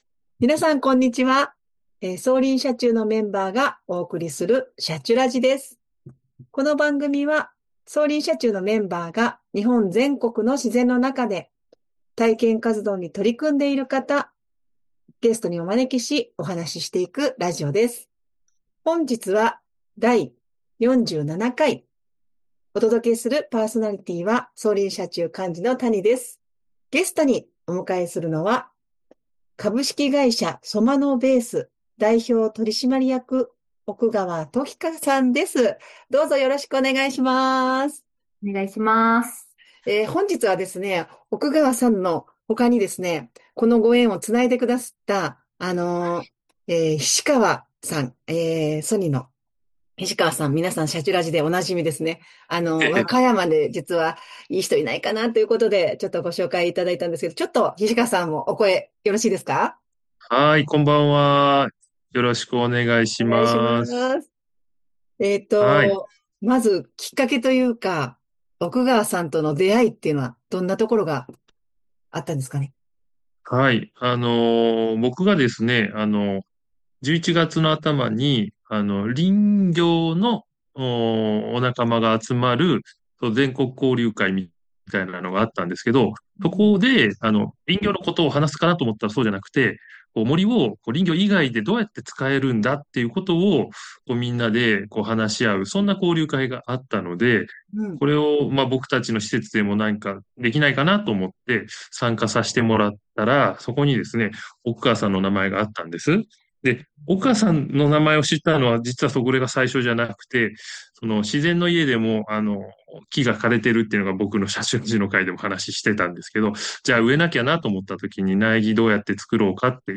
【今回のゲストスピーカー】